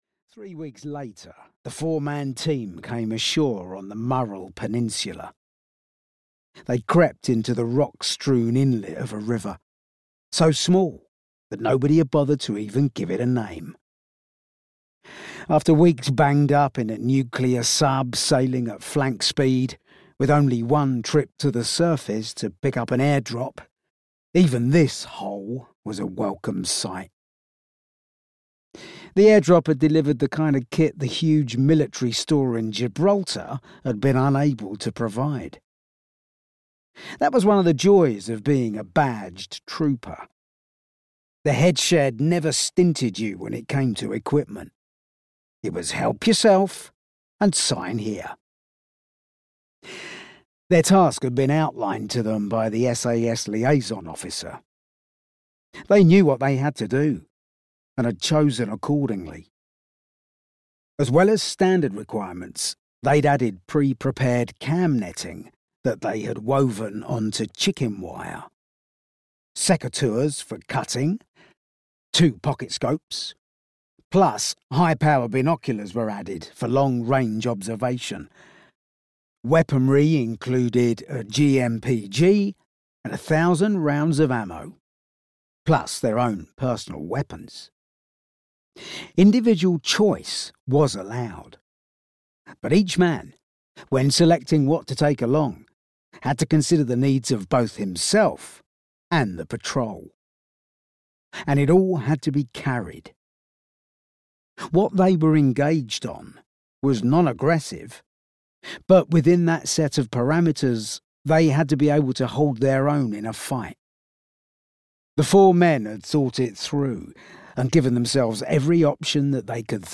Boat Troop: An SAS Thriller (EN) audiokniha
Ukázka z knihy